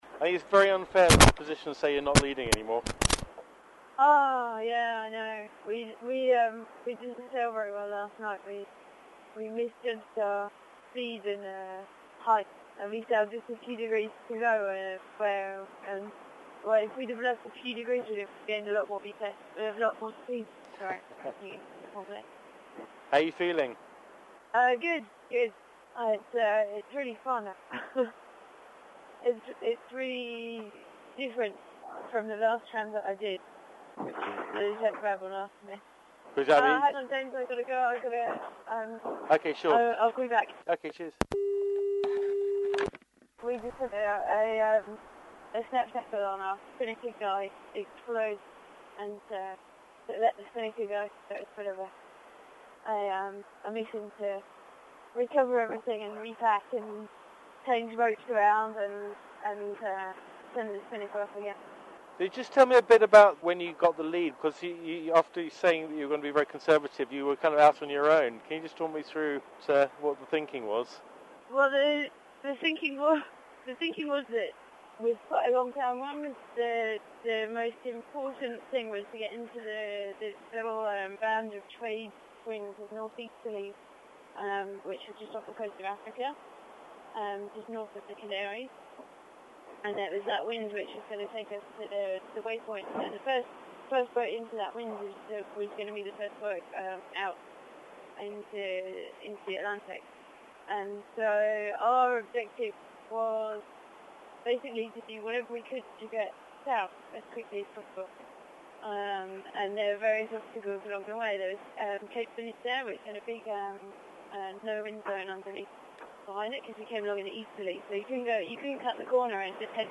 In this interview the girl wonder describes how they pulled into the lead and life on board
During the interview there was a brief intermission when the snap shackle on the spinnaker broke....